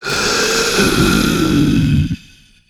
burer_die_2.ogg